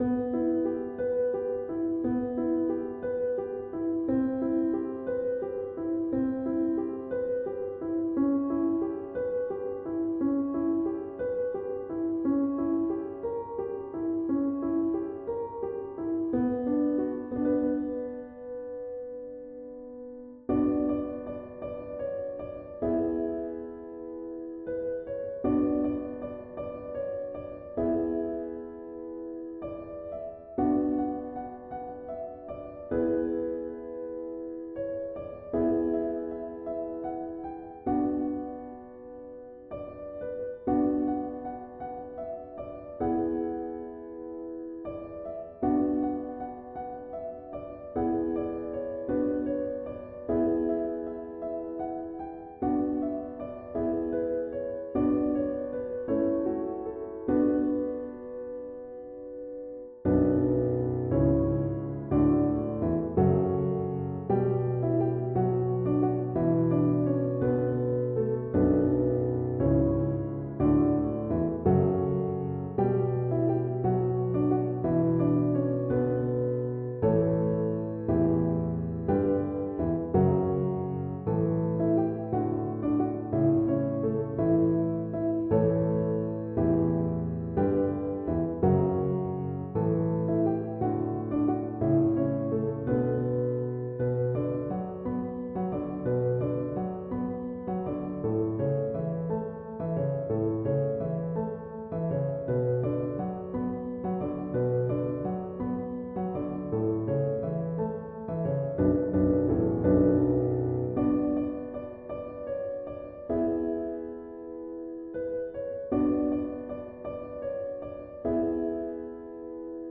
Musique pour les crédits :